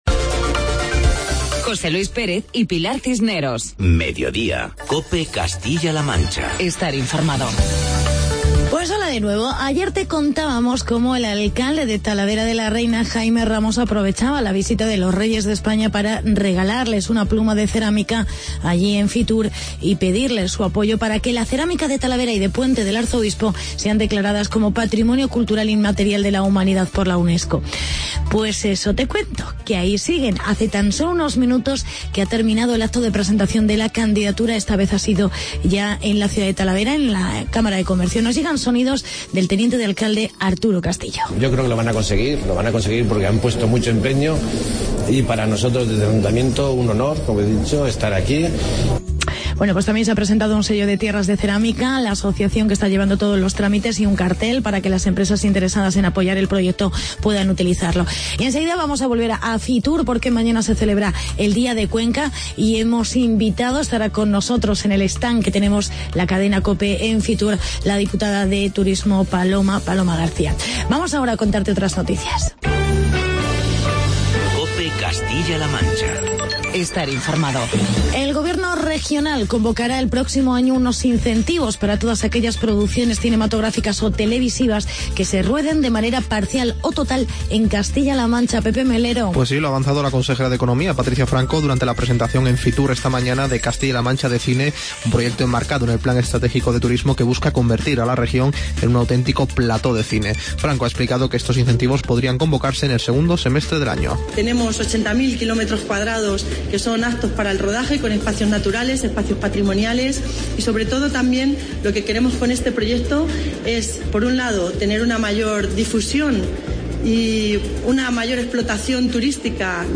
Especial Fitur con entrevista de la diputada de turismo de Cuenca, Paloma García.